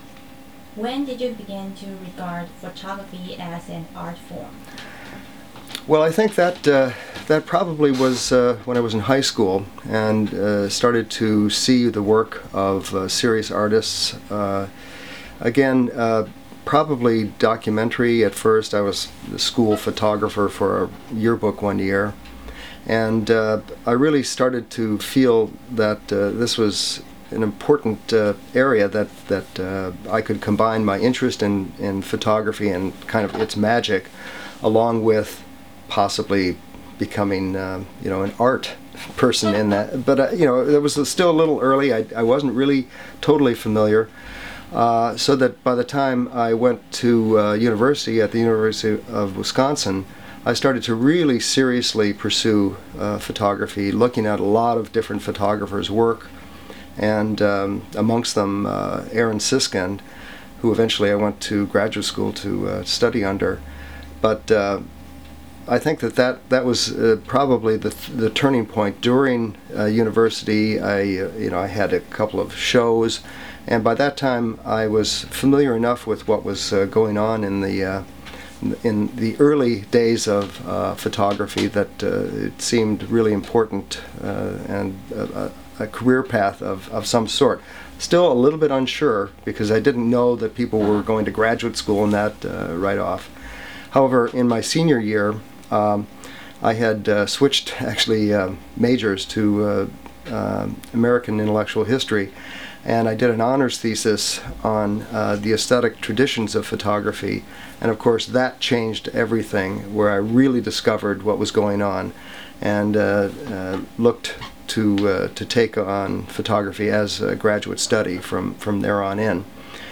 The identity of the interviewer in this recording is unknown.